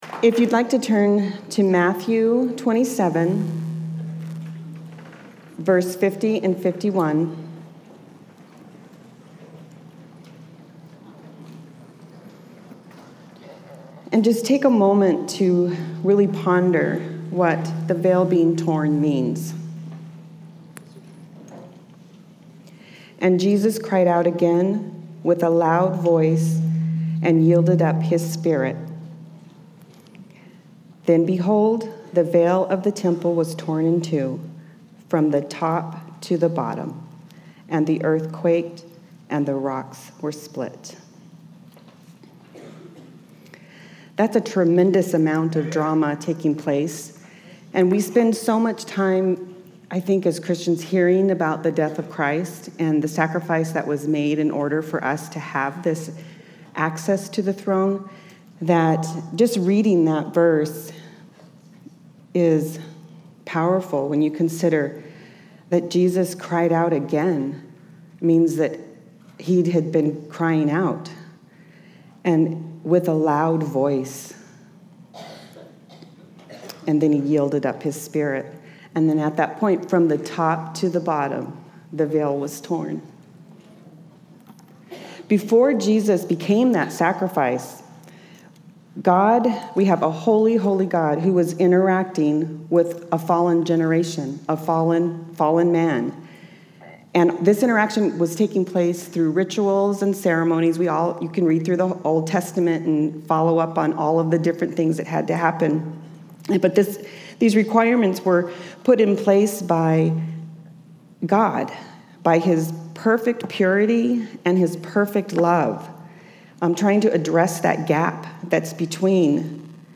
Women's Retreat 2018